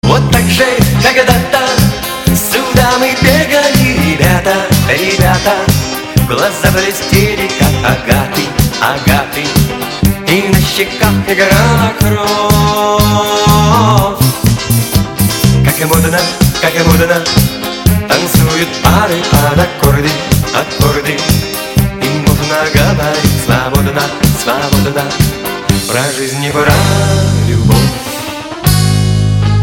• Качество: 160, Stereo
позитивные
добрые
цикличный
ностальгия